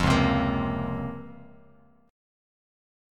DbM13 chord